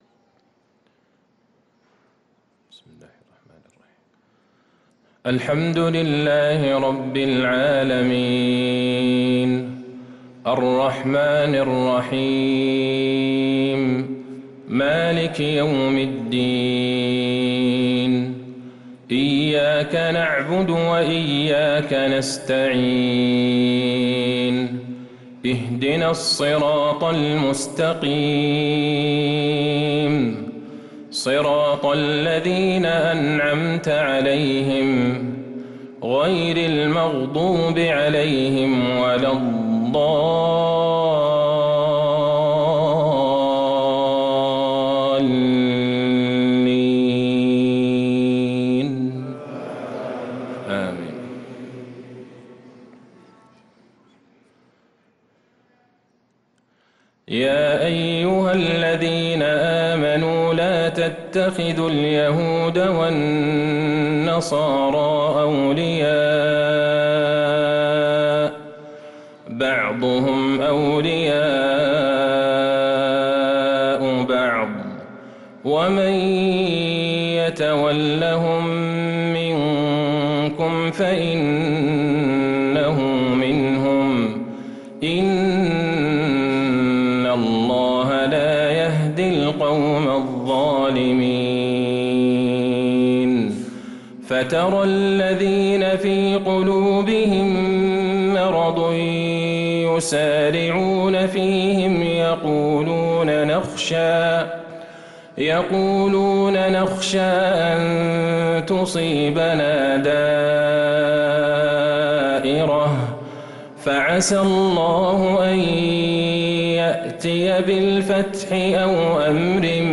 صلاة الفجر للقارئ عبدالله البعيجان 23 رمضان 1445 هـ
تِلَاوَات الْحَرَمَيْن .